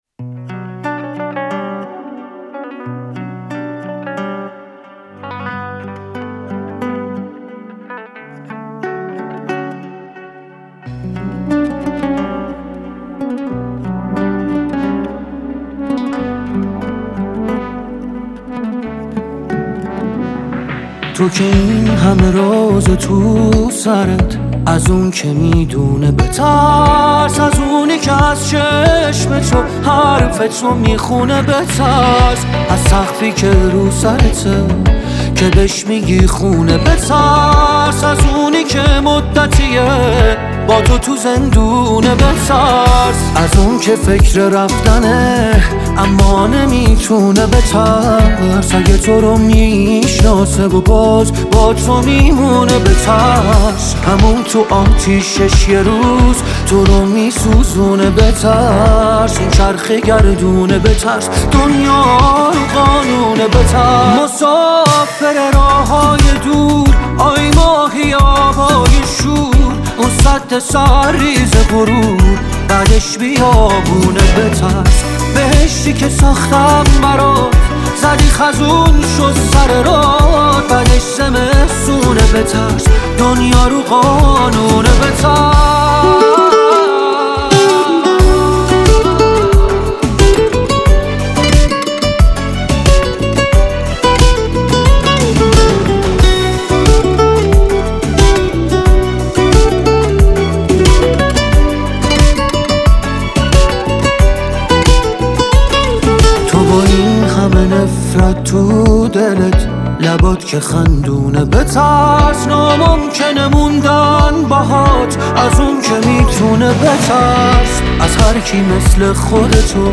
دانلود آهنگ پاپ ایرانی